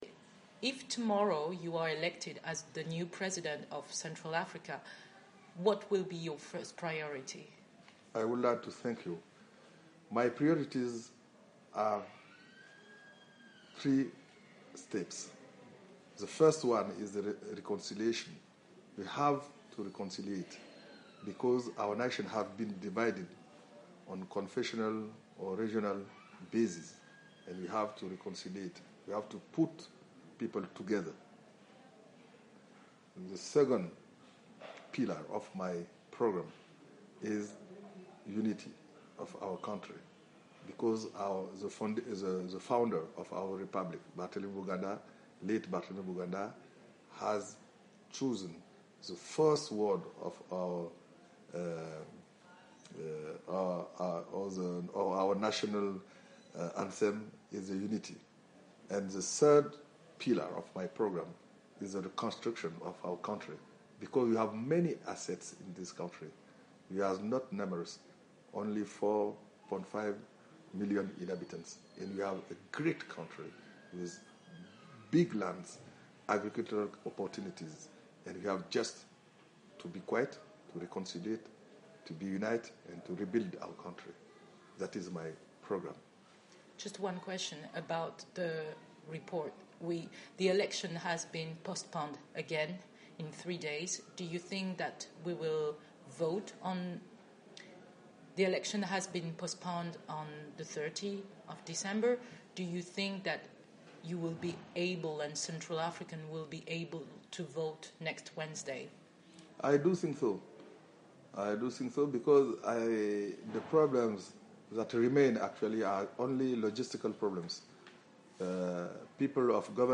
Interview with presidential candidate Martin Ziguele.
Martin Ziguele is among the 30 presidential candidates for this year's CAR elections. VOA Afrique interviewed him at his house.